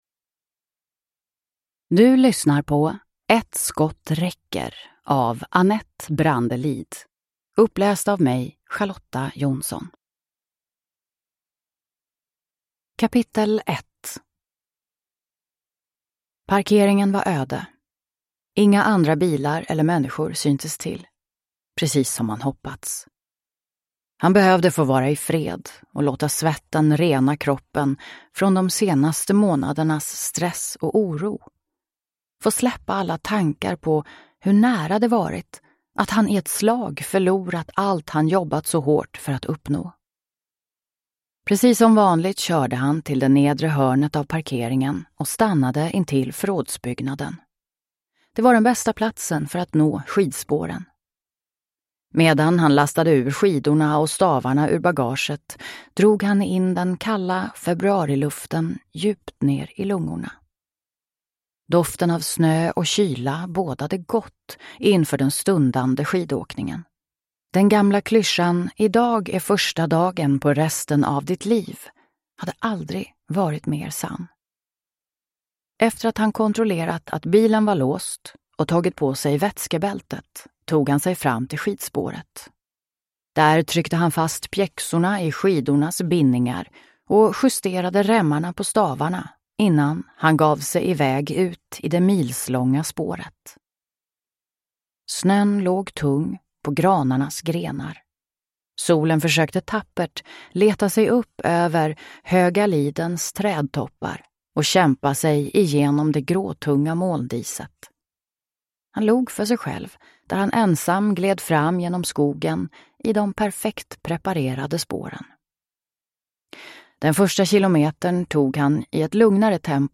Ett skott räcker (ljudbok) av Annette Brandelid Tunroth